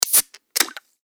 缶ジュースを開ける2.mp3